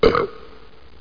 BURP.mp3